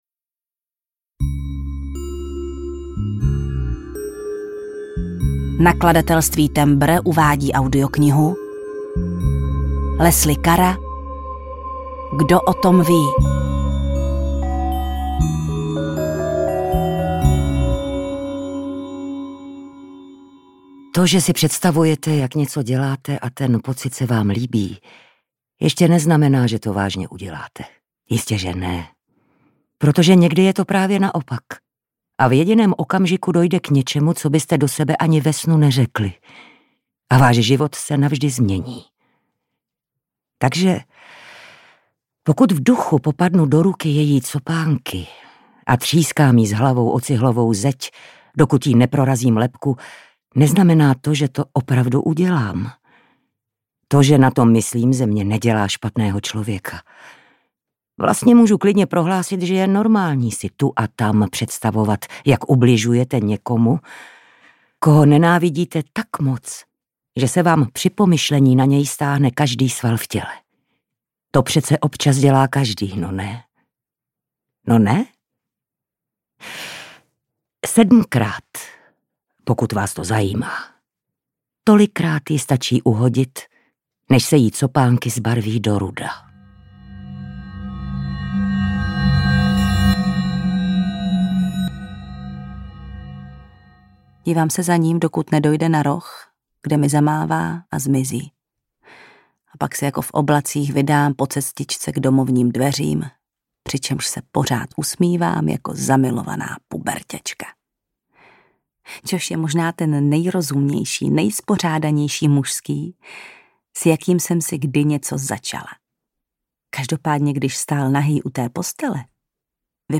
Kdo o tom ví? audiokniha
Ukázka z knihy
• InterpretJana Stryková, Lucie Juřičková